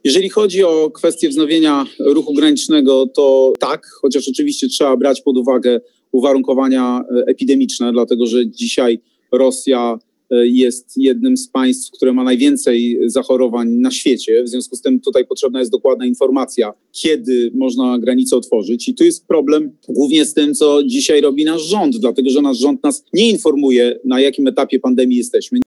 Rozmawialiśmy z Rafałem Trzaskowskim.